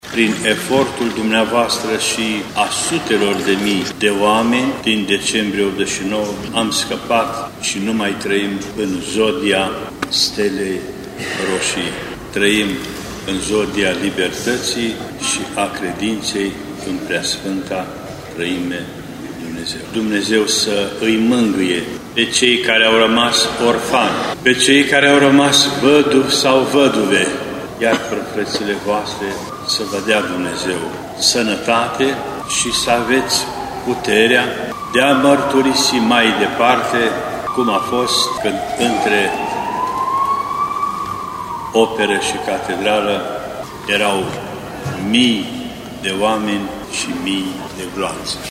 Pentru a cinsti memoria eroilor revoluției, mai mulți timișoreni au mers astăzi la catedrala mitropolitană pentru slujba de pomenire a eroilor din Decembrie 1989.
Slujba dedicată evenimentelor din 17 decembrie 1989 a fost oficiată de Înalt Prea Sfinţitul Ioan Selejan, mitropolit al Banatului, care a avut şi un mesaj pentru timişorenii care au supravieţuit în Decembrie 89: